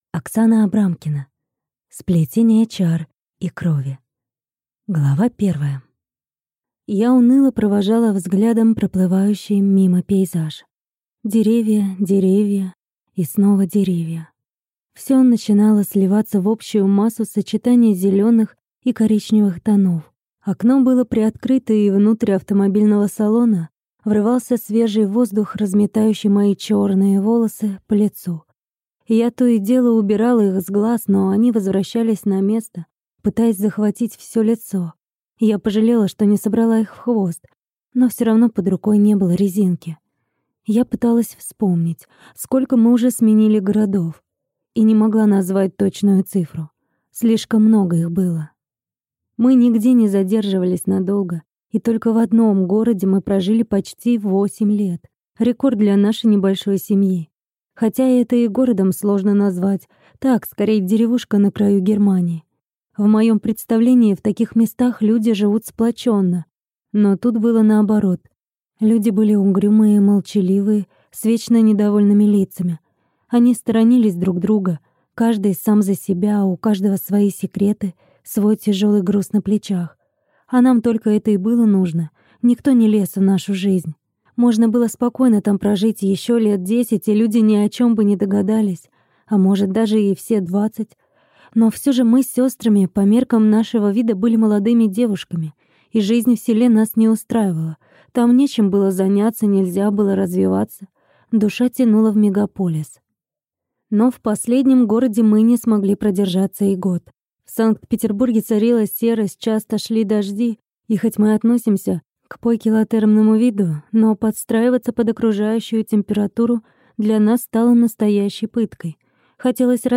Аудиокнига Сплетение чар и крови | Библиотека аудиокниг